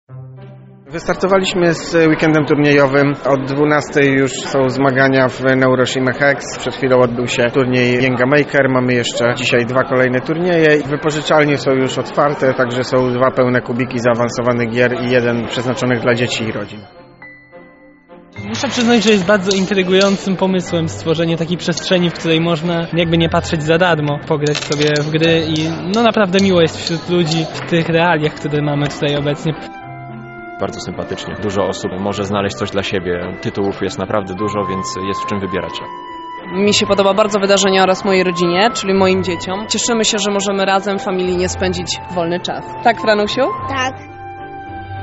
Na miejscu zapytaliśmy o wrażenia związane z wydarzeniem.